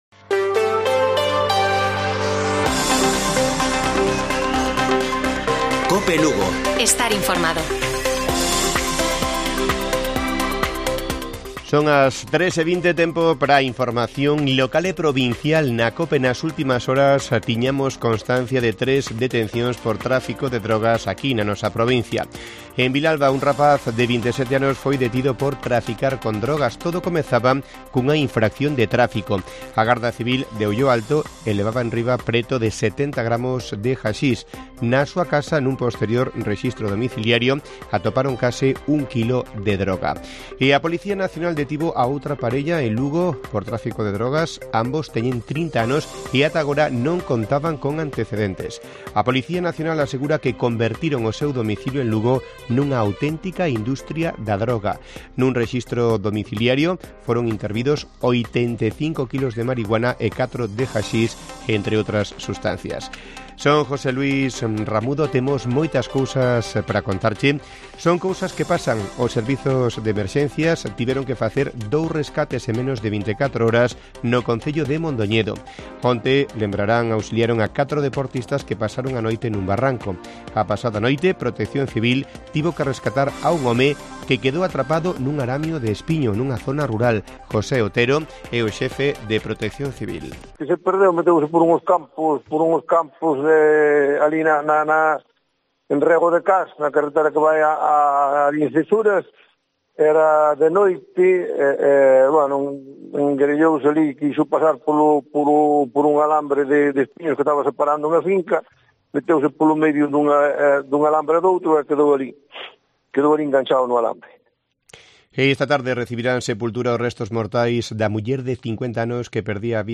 Informativo Mediodía de Cope Lugo. 31 DE MAYO. 14:20 horas